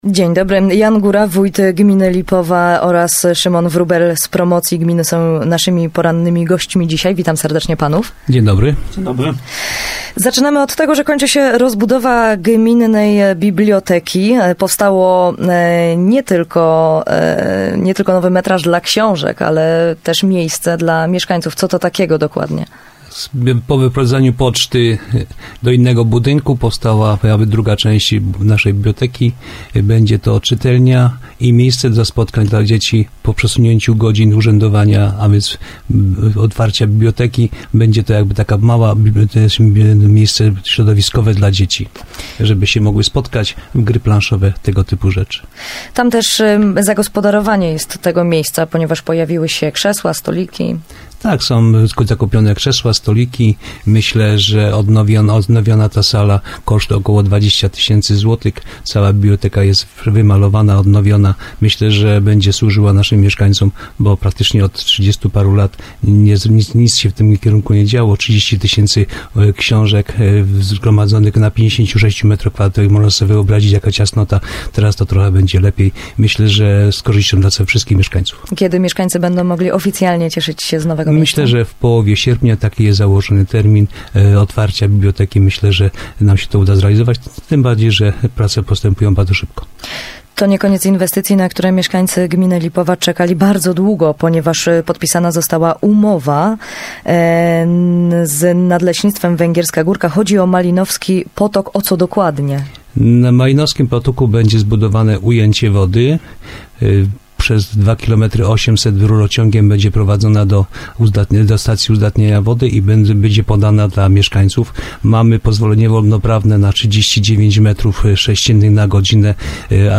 Wójt Gminy Lipowa gościem porannego programu Radia Bielsko